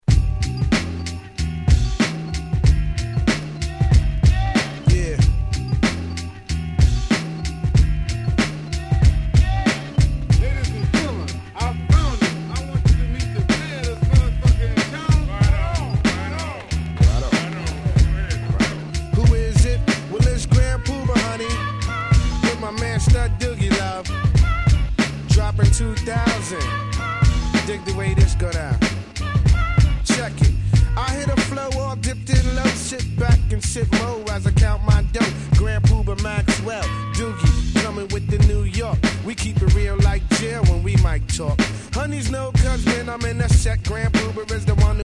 95' Hip Hop Classics !!
Jazzyなトラックが非常に気持ち良いです！！
90's Boom Bap ブーンバップ